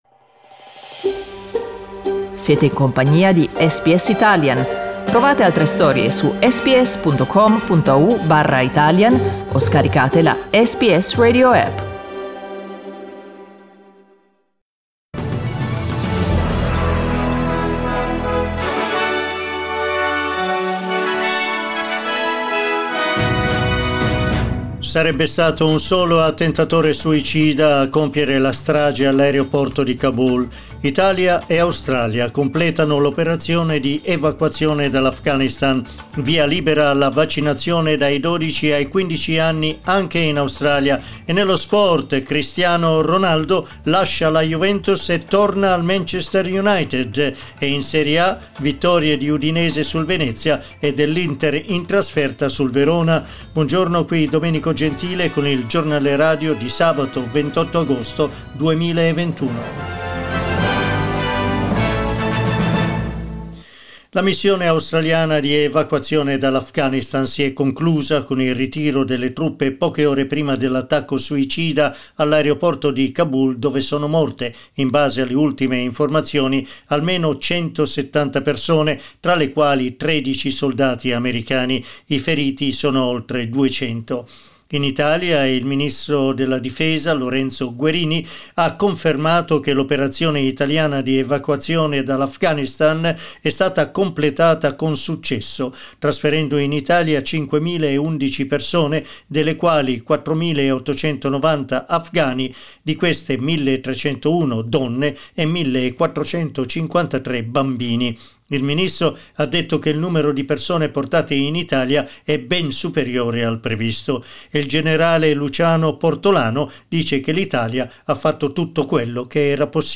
Giornale radio sabato 28 agosto 2021
Il notiziario di SBS in italiano.